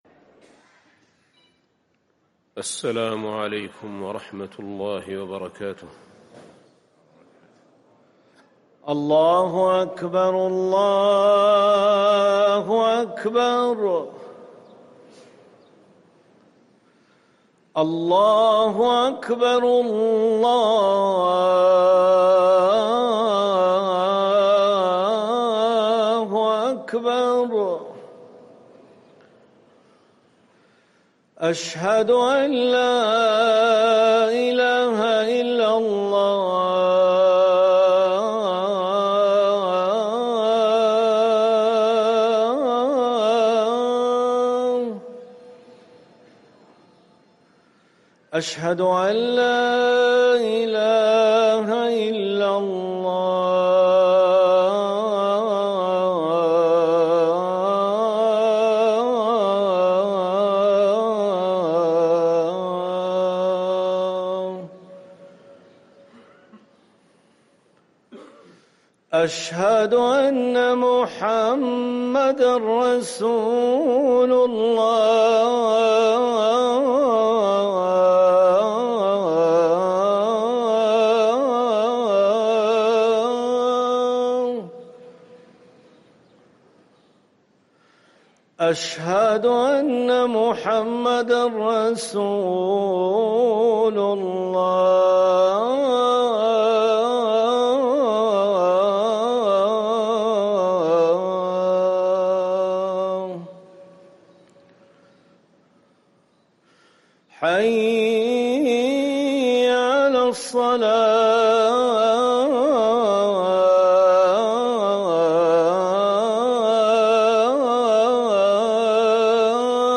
اذان الجمعة الثاني